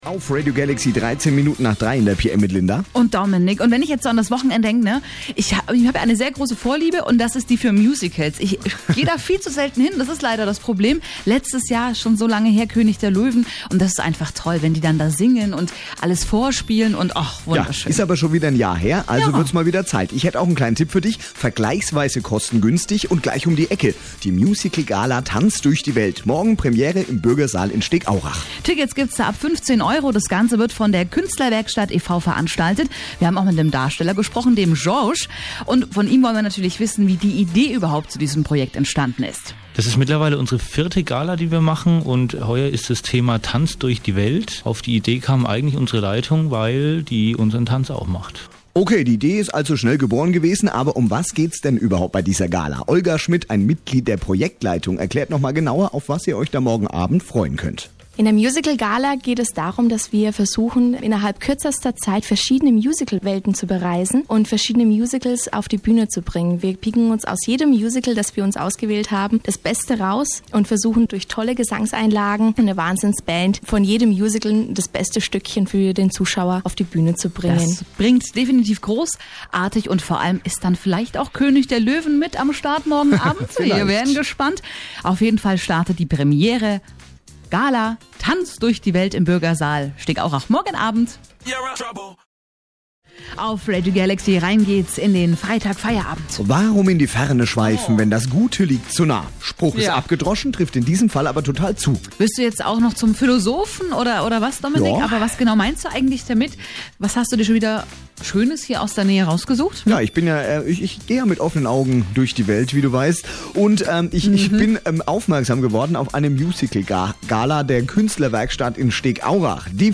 "Tanz durch die Welt" im Radio